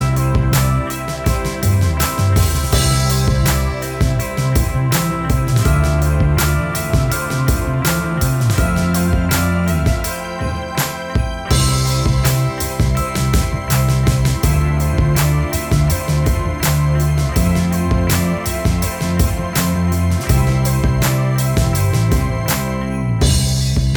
Minus Acoustic Guitar Pop (2010s) 3:55 Buy £1.50